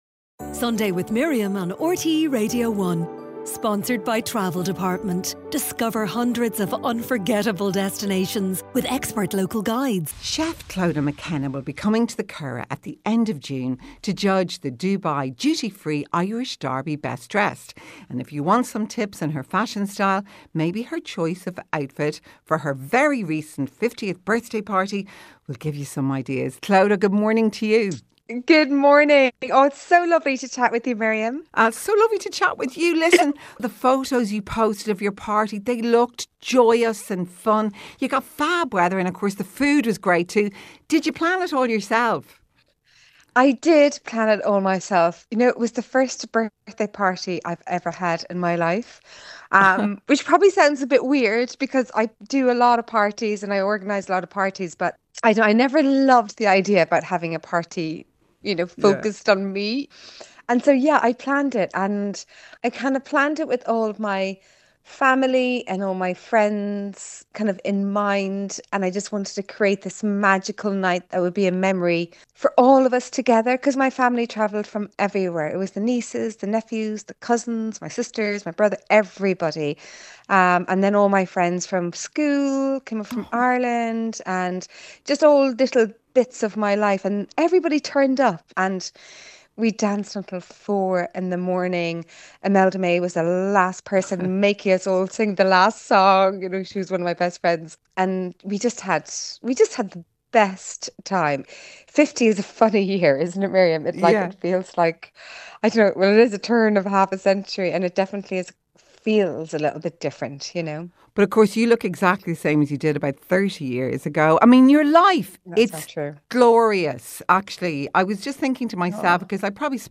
At the end of June, chef Clodagh McKenna will be at the Curragh to judge the ‘Best Dressed’ competition for the Dubai Duty Free Irish Derby. This morning she chatted with Miriam about her signature style, the excitement around the competition, and what’s new in her life.